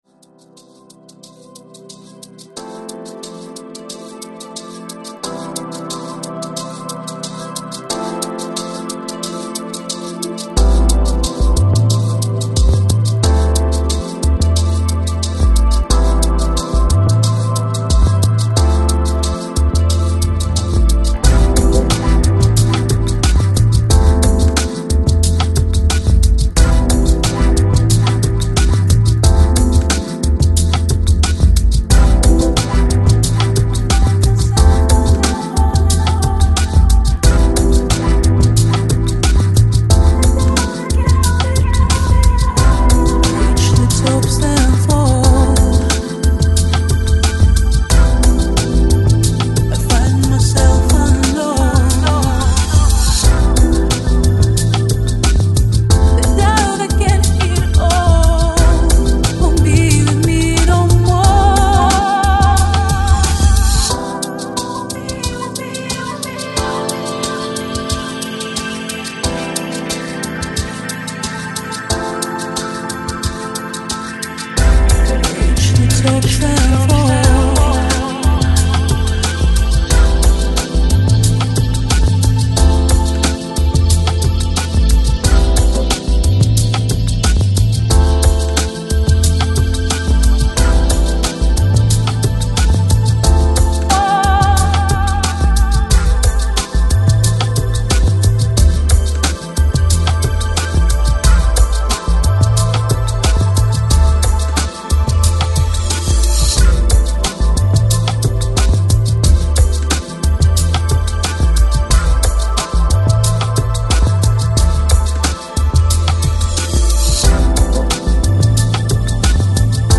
Жанр: Electronic, Lounge, Chill Out, Balearic, Downtempo